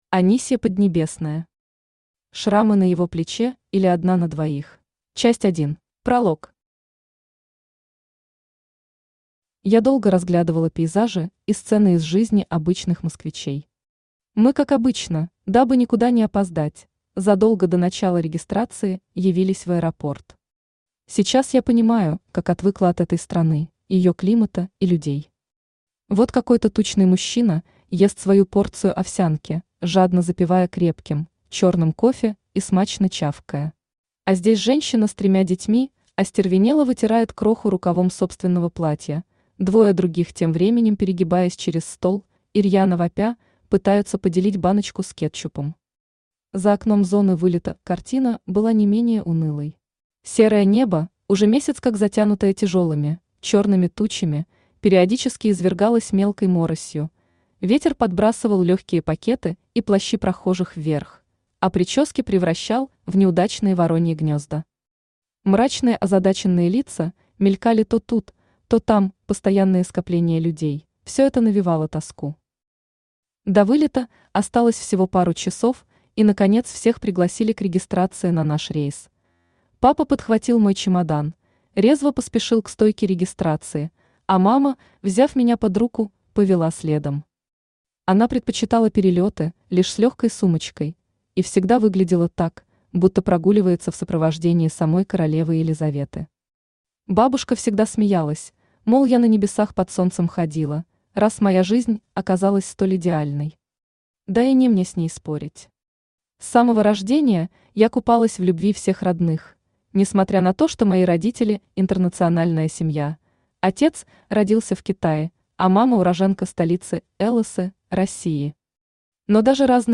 Аудиокнига Шрамы на его плече, или Одна на двоих | Библиотека аудиокниг
Читает аудиокнигу Авточтец ЛитРес.